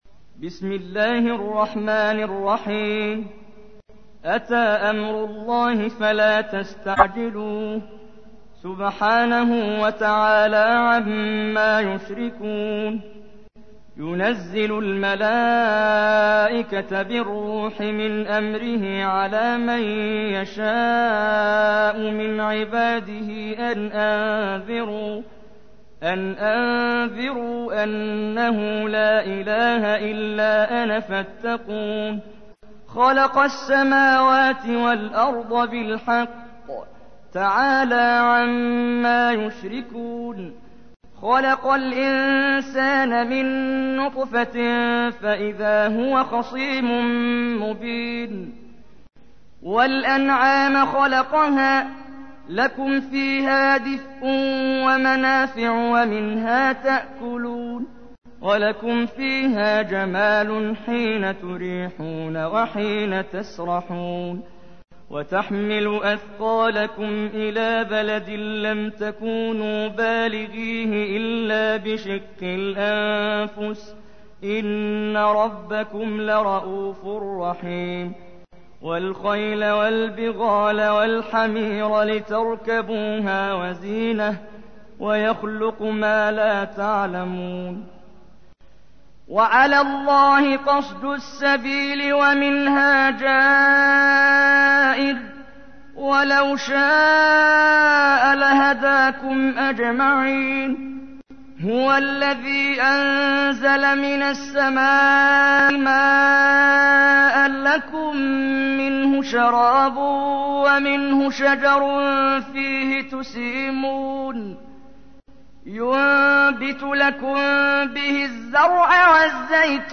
تحميل : 16. سورة النحل / القارئ محمد جبريل / القرآن الكريم / موقع يا حسين